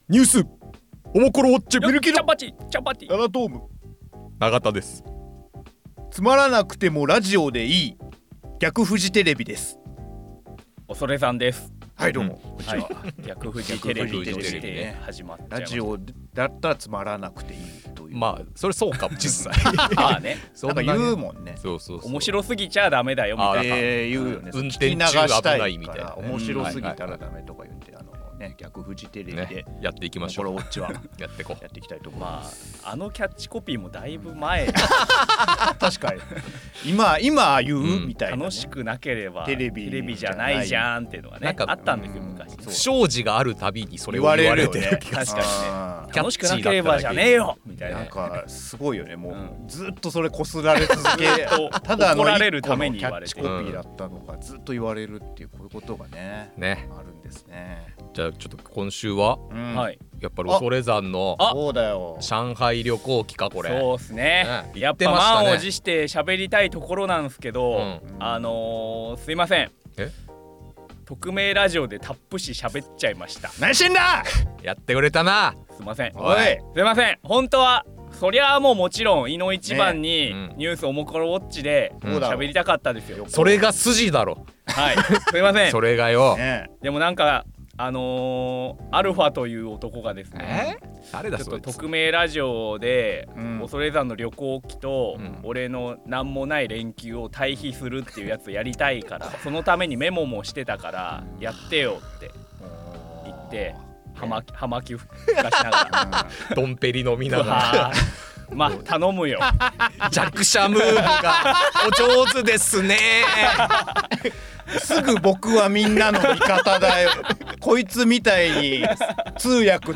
オモコロ編集部の3人が気になるニュースについて語ります。